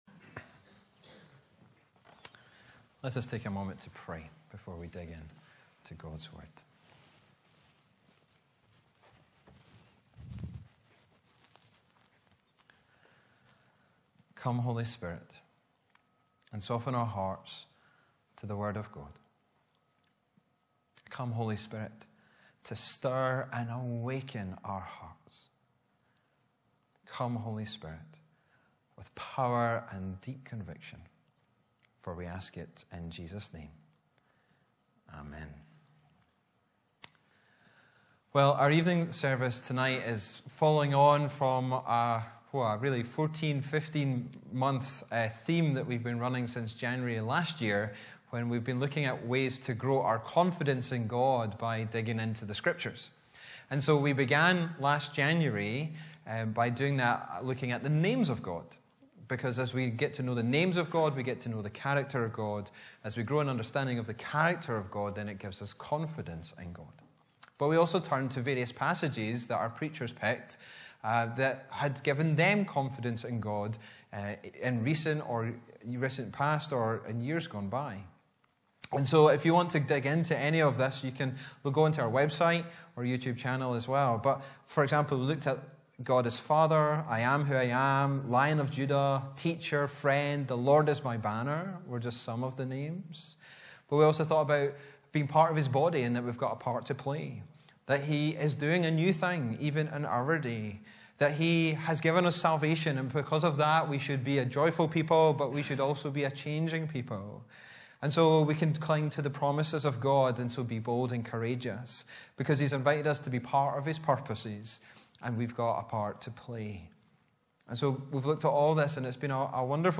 Sermons in this Series
Bible references: Luke 24:13-35 Location: Brightons Parish Church